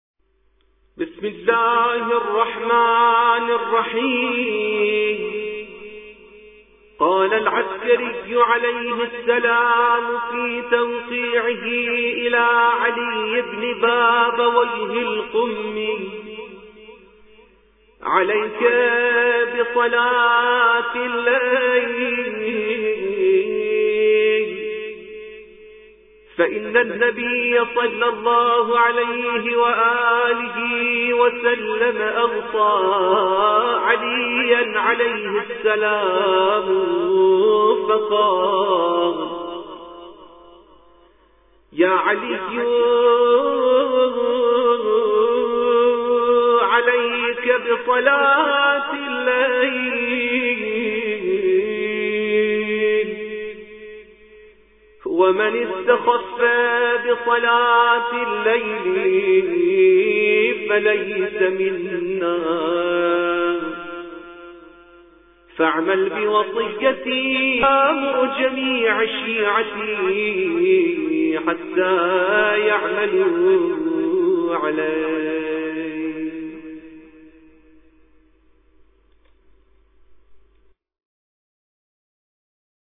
قاريء-غير-معروف--خطبة-الإمام-العسکري-ع-عليك-بصلاة-اللیل